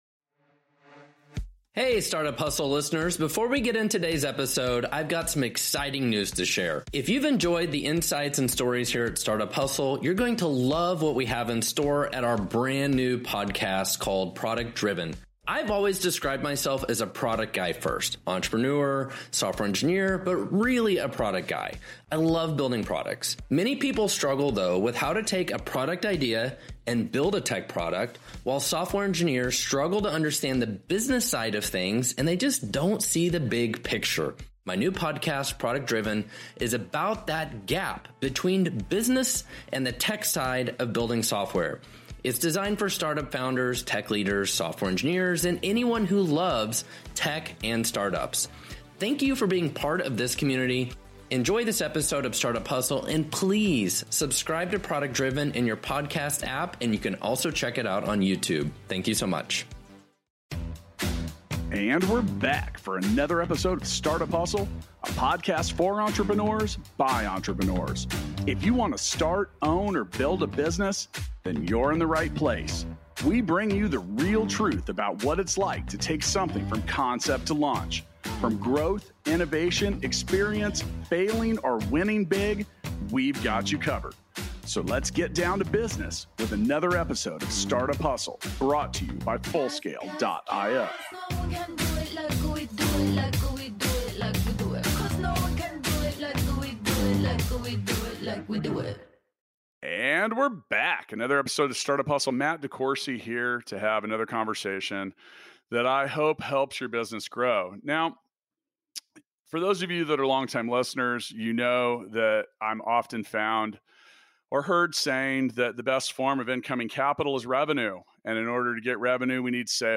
an in-depth conversation